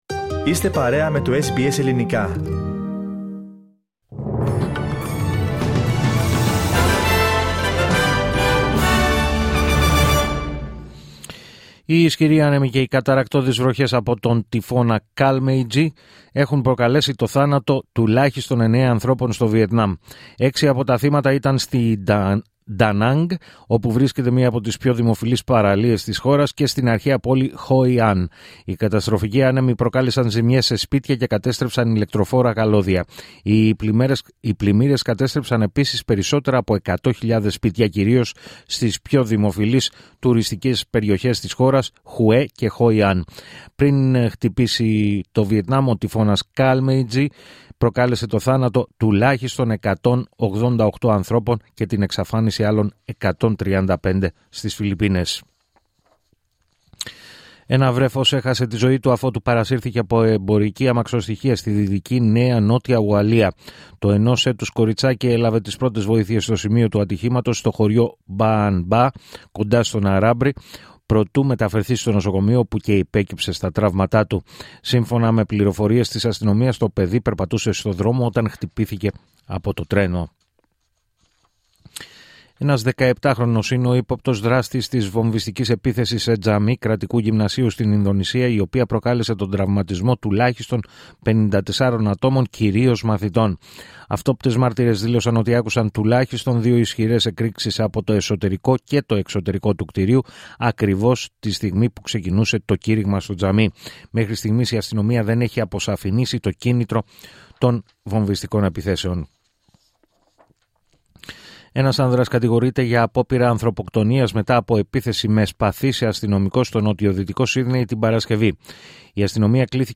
Δελτίο Ειδήσεων Σάββατο 8 Νοεμβρίου 2025